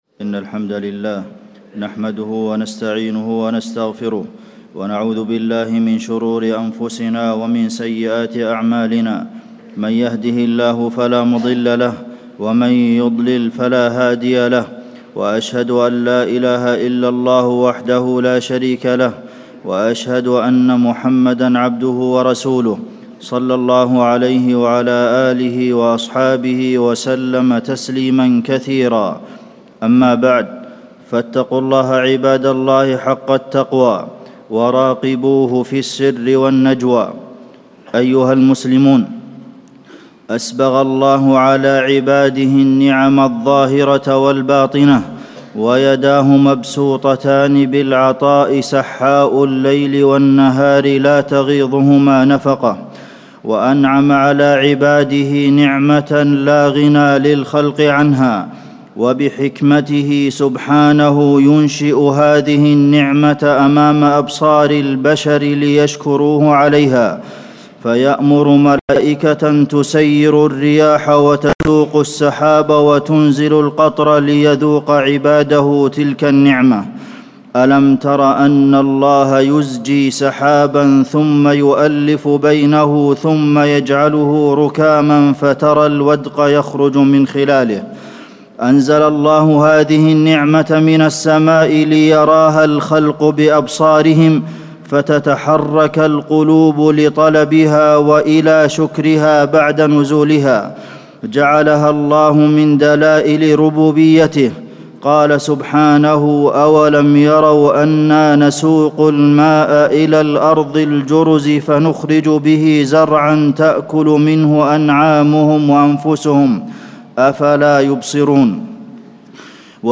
تاريخ النشر ١٣ ربيع الأول ١٤٣٩ هـ المكان: المسجد النبوي الشيخ: فضيلة الشيخ د. عبدالمحسن بن محمد القاسم فضيلة الشيخ د. عبدالمحسن بن محمد القاسم نعمة الماء ووجوب شكرها